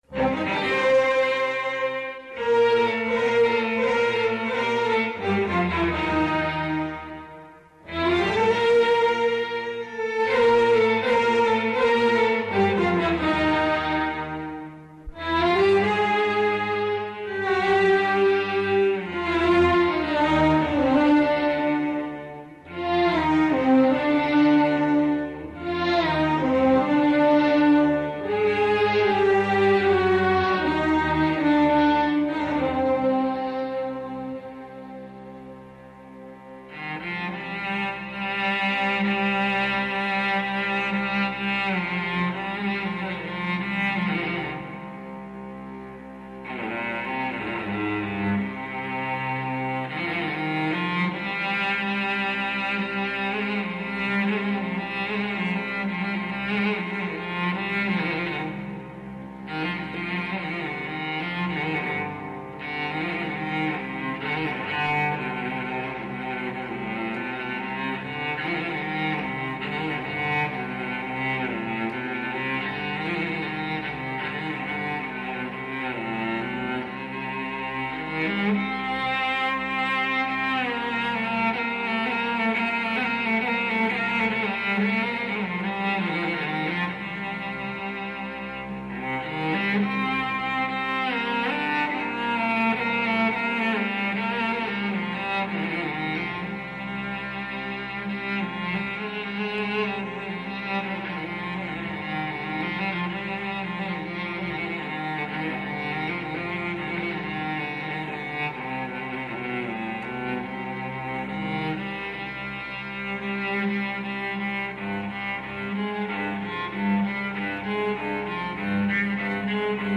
عازف التشيلو
بتسجيل خاص ونادر
بحيث تبدأ بعزف منفرد